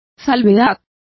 Complete with pronunciation of the translation of qualification.